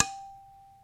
ding hit metal ring ting tone sound effect free sound royalty free Sound Effects